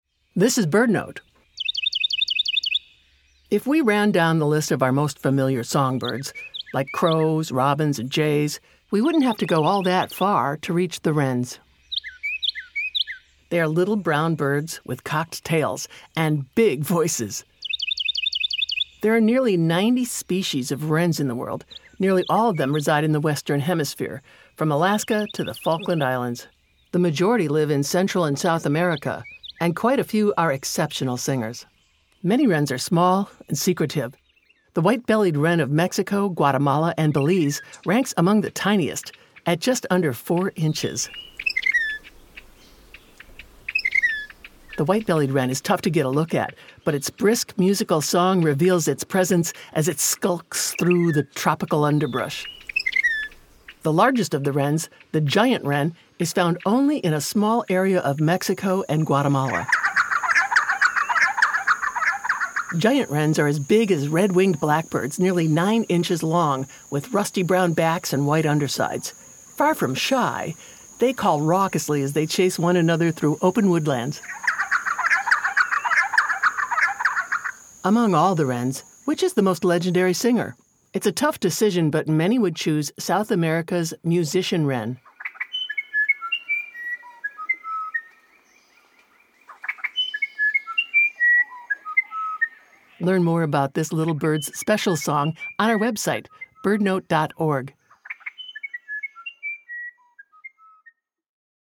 It’s a tough decision, but many would choose this Musician Wren from South America.